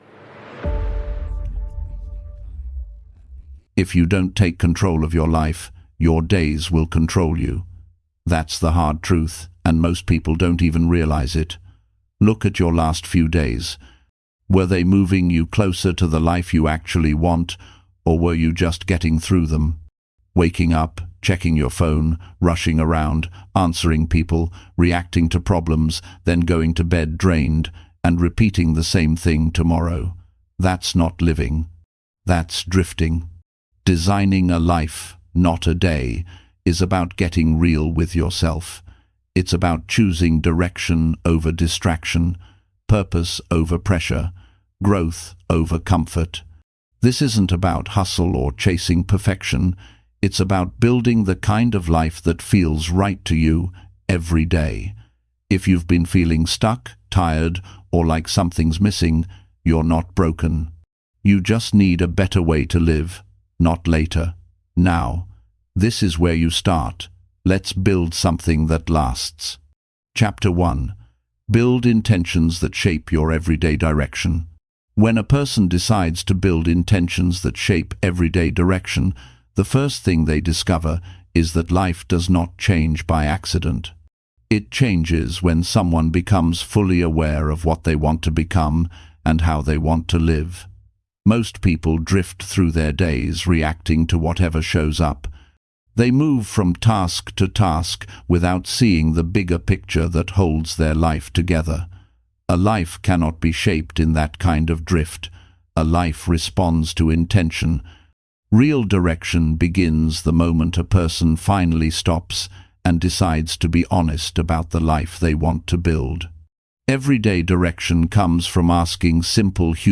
Libros Narrados Wake Up Between 3AM–5AM?